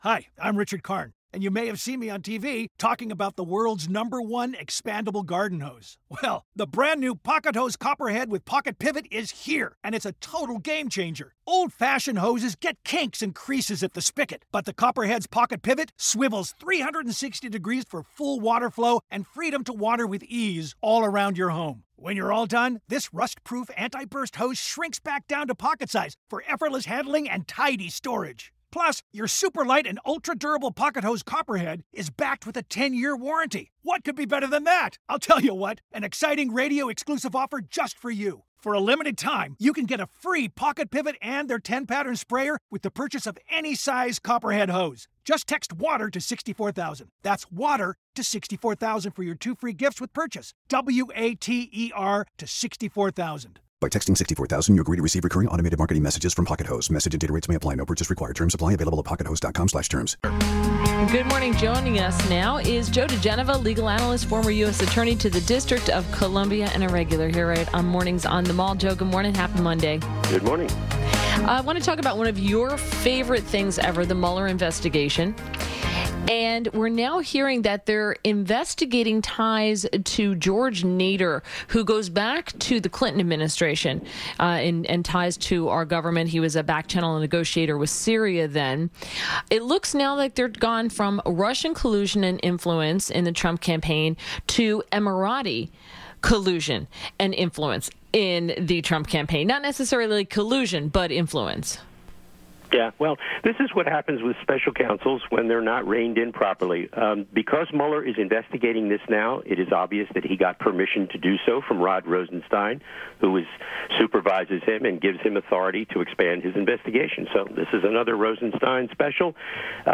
WMAL Interview - JOE DIGENOVA - 03.05.18